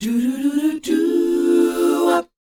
DOWOP G AU.wav